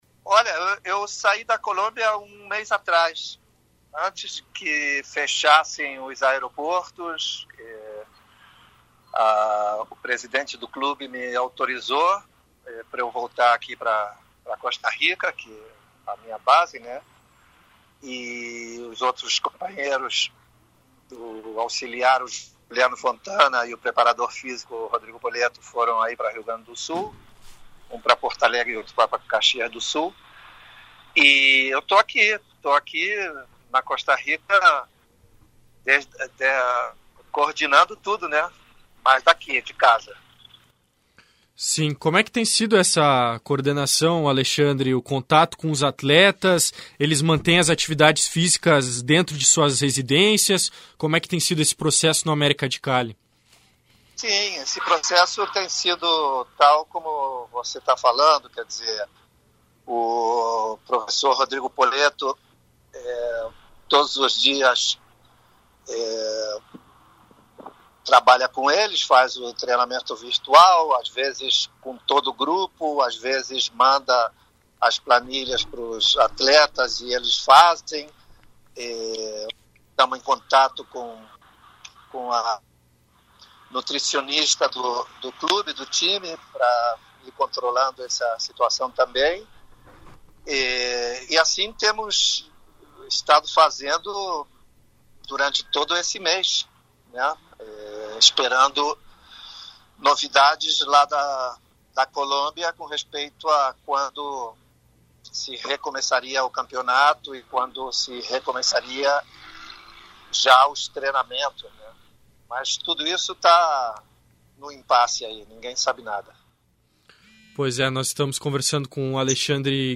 Adversário da dupla Grenal na fase de grupos da Copa Libertadores, o América de Cali, da Colômbia, vive a mesma situação de paralisação das atividades no futebol. Comandado pelo brasileiro, Alexandre Guimarães, o clube tenta manter as atividades de forma virtual e segue sem previsibilidade do retorno, conforme relatou o técnico em entrevista exclusiva à Rádio Grenal.